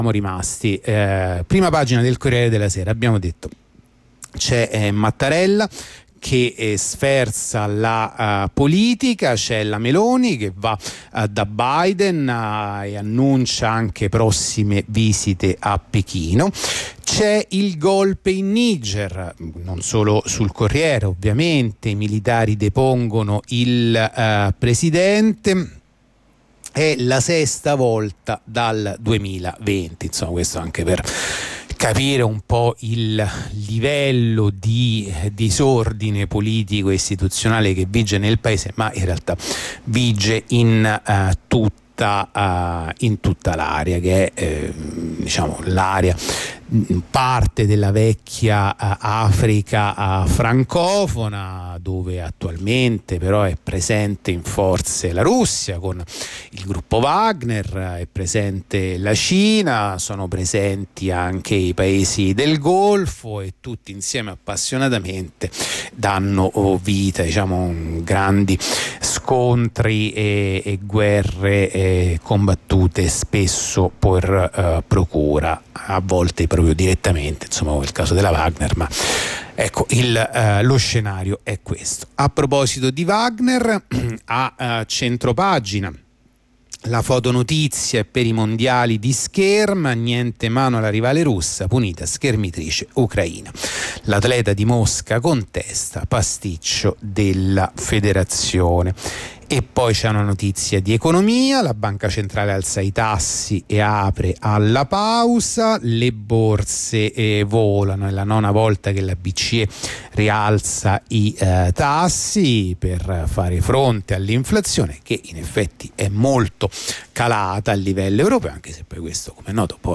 La rassegna stampa di venerdì 28 luglio 2023
La rassegna stampa di radio onda rossa andata in onda venerdì 28 luglio 2023